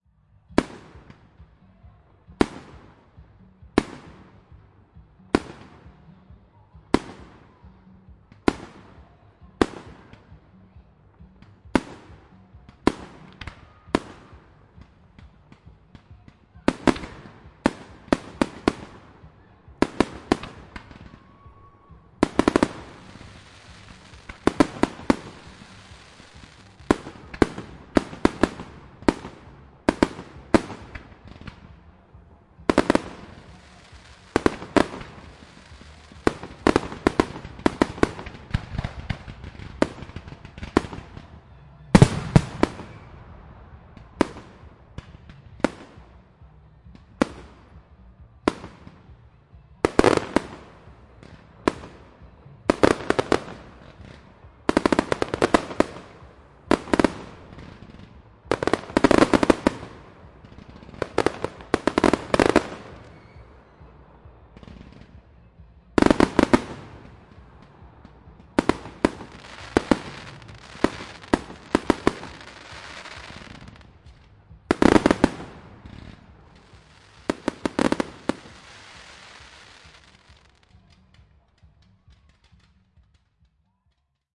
木笛比较 " 烟花, 关闭, D ( H4n )
描述：烟花的未加工的音频显示在Godalming，英国。我用Zoom H1和Zoom H4n Pro同时录制了这个事件来比较质量。令人讨厌的是，组织者还在活动期间抨击了音乐，因此安静的时刻被遥远的，虽然模糊不清的音乐所污染。